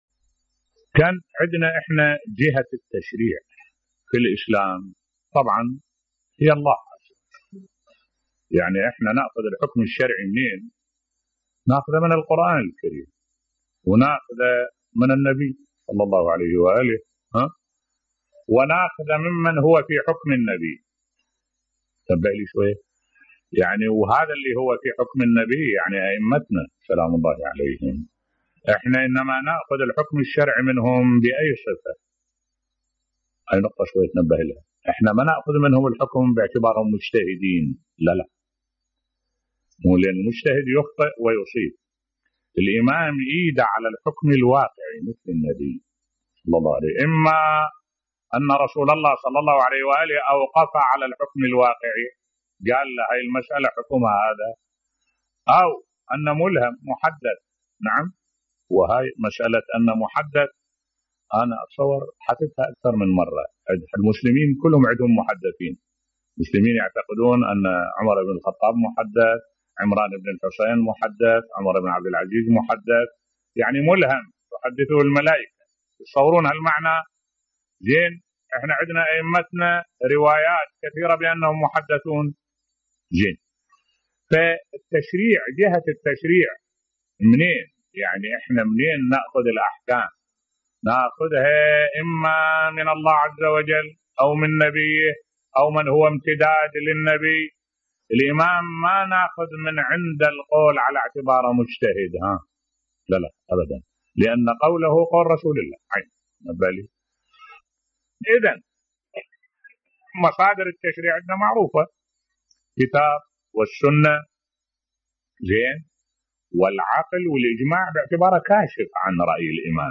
ملف صوتی الولاية التشريعية لأئمة أهل البيت بصوت الشيخ الدكتور أحمد الوائلي